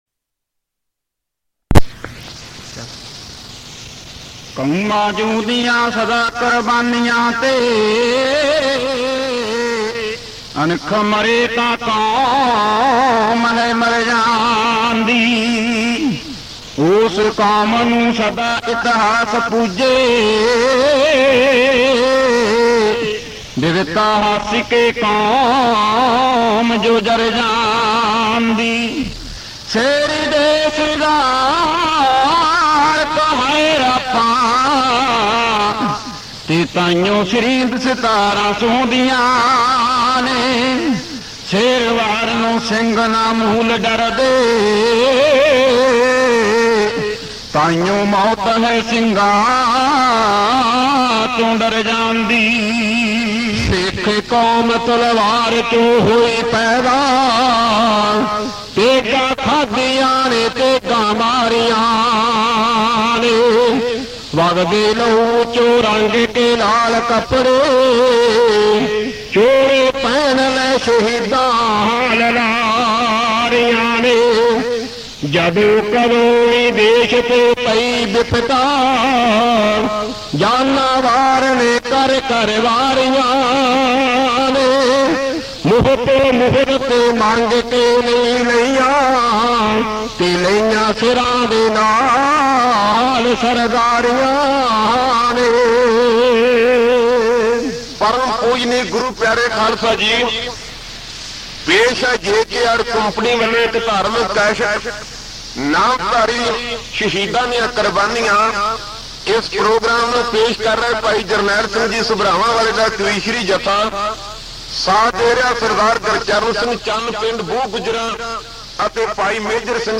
Genre: Kavishr